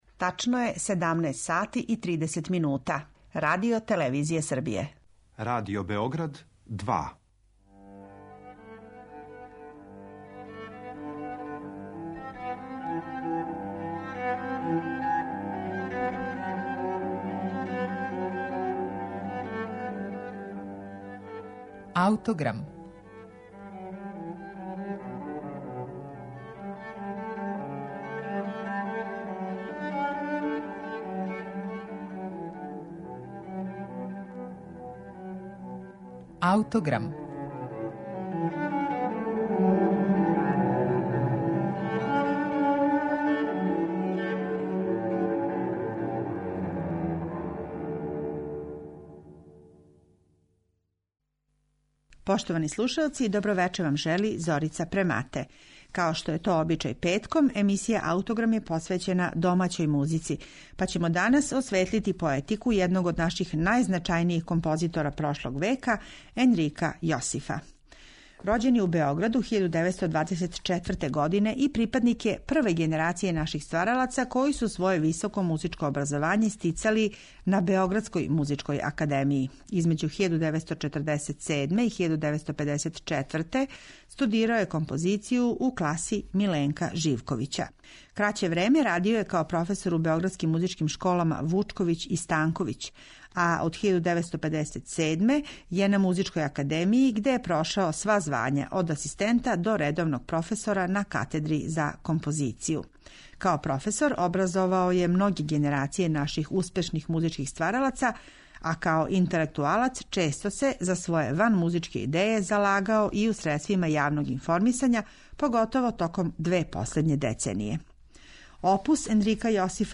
Петком је емисија по традицији посвећена домаћем музичком стваралаштву, а овога пута слушаћете дела за флауту и за хор флаута ЕНРИКА ЈОСИФА.
Емисију ће започети концертни снимак из 1988. године, на коме је забележена Јосифова композиција "Песмена говорења", за флауту-соло, хор флаута и виолончело.